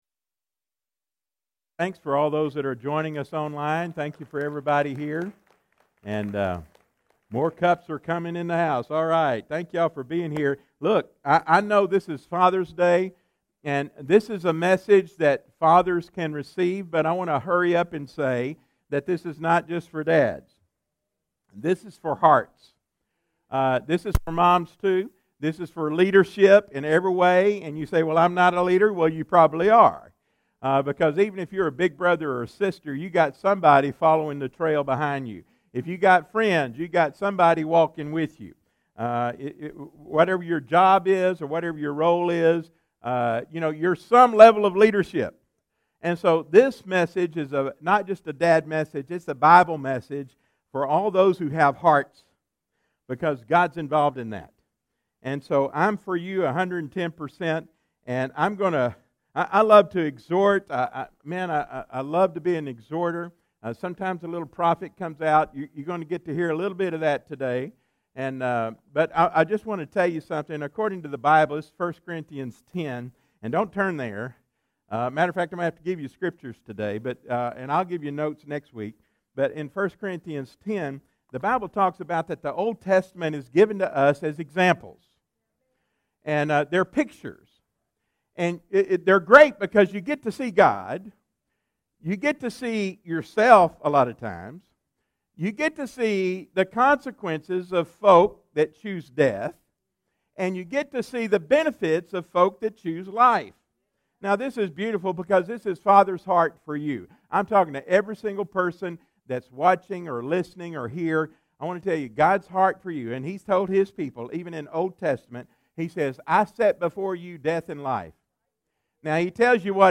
a Special Father's Day message